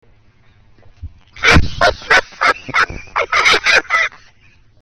Witch Laugh 3